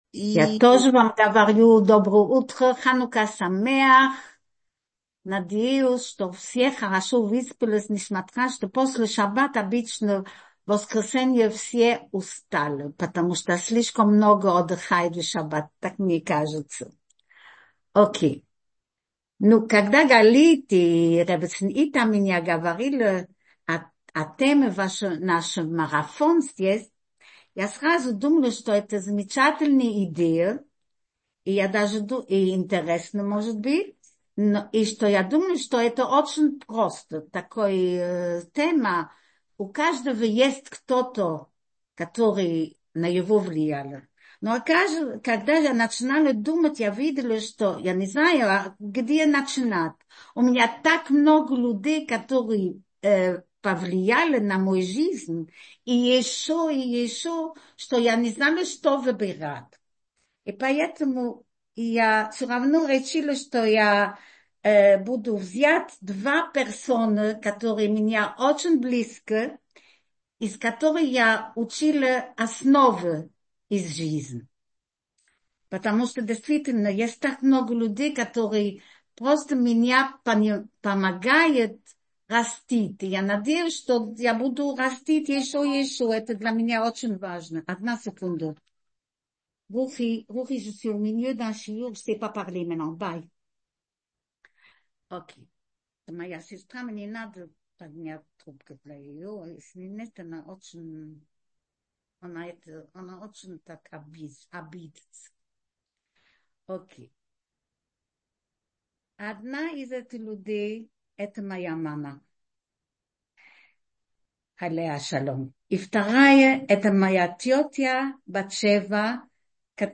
Утреннее Zoom ток-шоу «Утро с Толдот» приглашает вас на наш традиционный ханукальный Зум-марафон с кратким «спринт»-включением наших лекторов каждый день праздника.